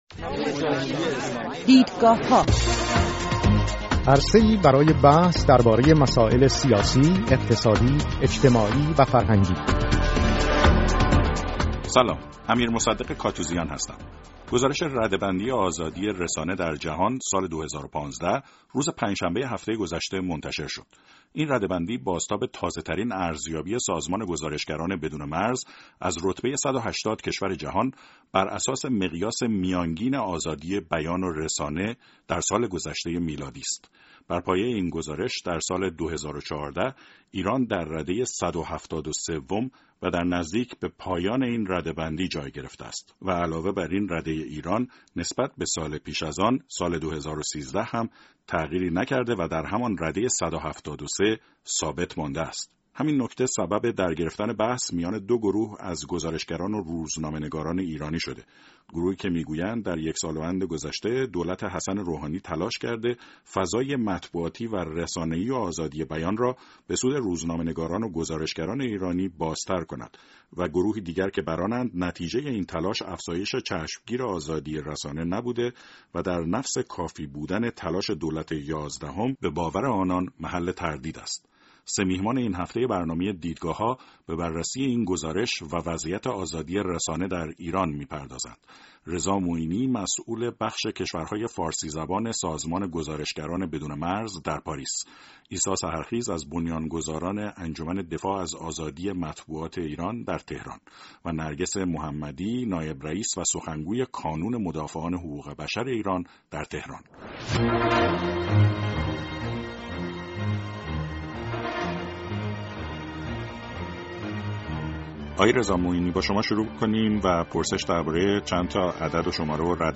سه میهمان این هفته برنامه «دیدگاه‌ها» به بررسی و وضعیت آزادی رسانه در ایران بر پایه رده بندی سالانه گزارشگران بدون مرز و مقایسه شاخص های آزادی بیان و مطبوعات در ۱۸۰ کشور جهان می‌پردازند.